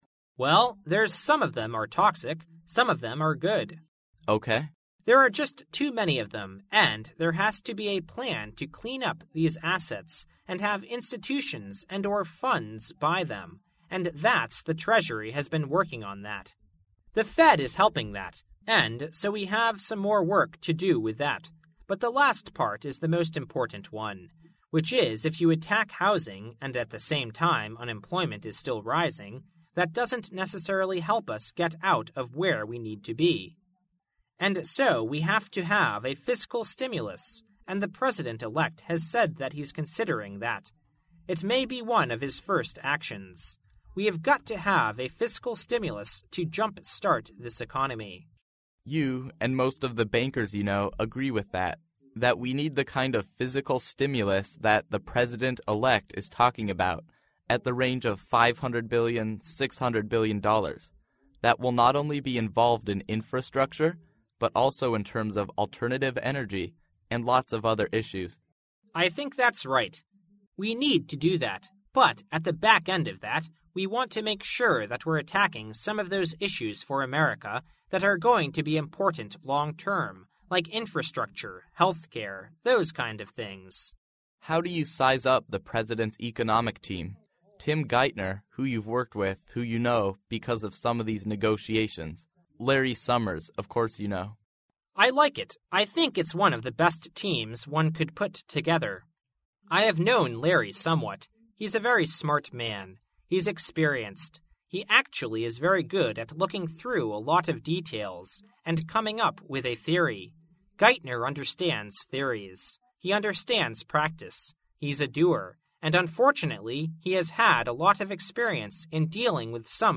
世界500强CEO访谈 第29期:花旗集团潘迪特 尽力确保增加股本总额(2) 听力文件下载—在线英语听力室